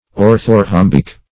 Search Result for " orthorhombic" : The Collaborative International Dictionary of English v.0.48: Orthorhombic \Or`tho*rhom"bic\, a. [Ortho- + rhombic.]